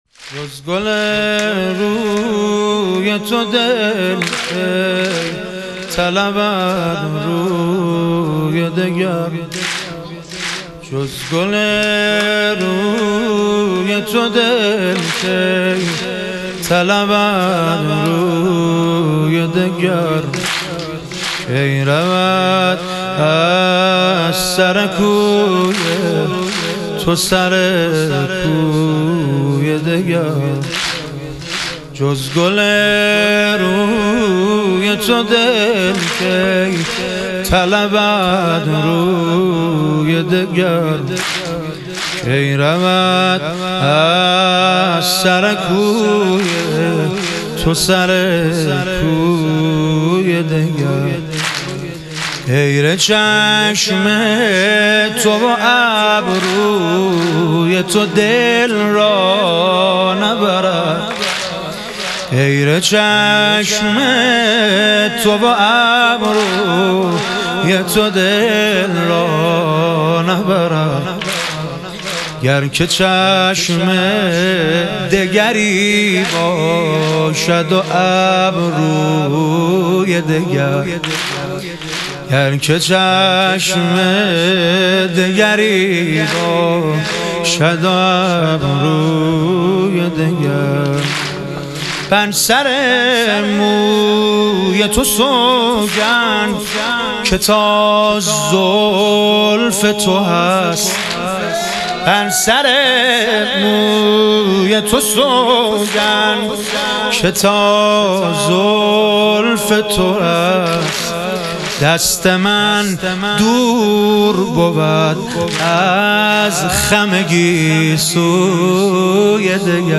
شهادت امام جواد علیه السلام - واحد - 4 - 1404